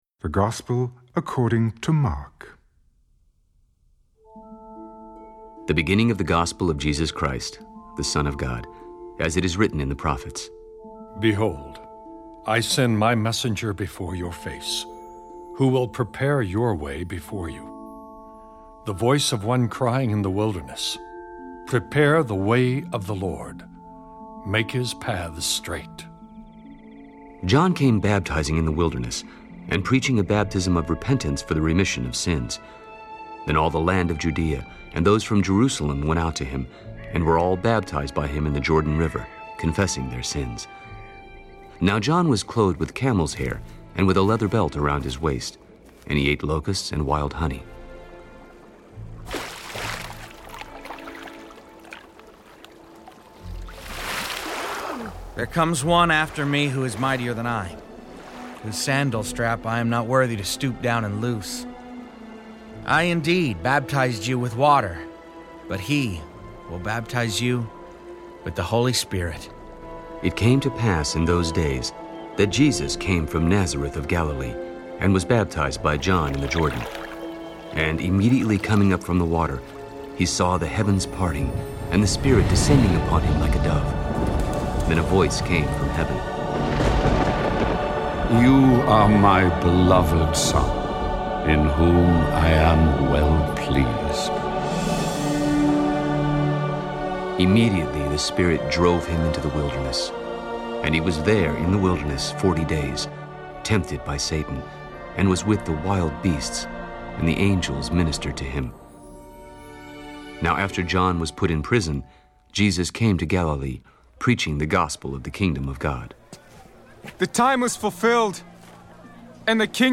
• Fully Dramatized Audio Bible reading